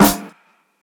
xdxdfghs-snr.wav